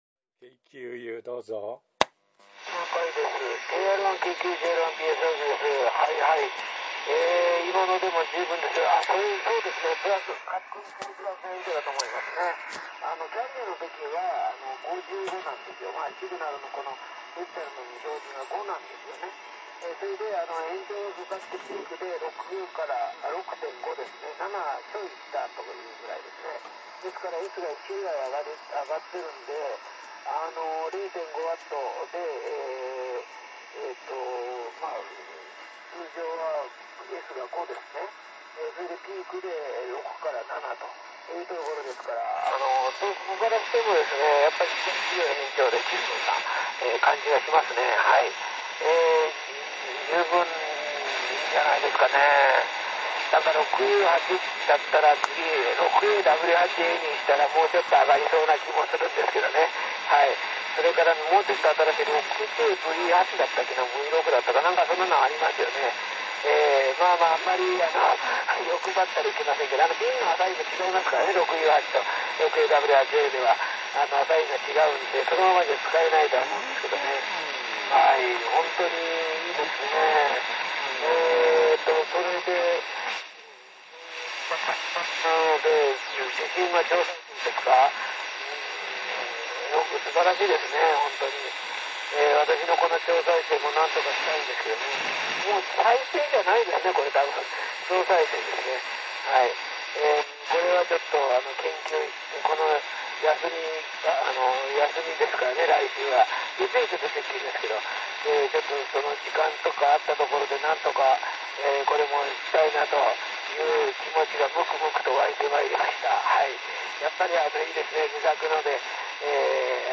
ラジオ型 50 MHz AM トランシーバー 隕石 3 号竣工式です。
送信部：6U8A 単球 変調器 12AX7A - 6ZP1 ハイシング変調
マイク：クリスタルスピーカーを使った自作クリスタルマイク
6ZP1 変調器で、アスタティックマイクでもソフトな聞きやすい音だそうです。
竣工式の様子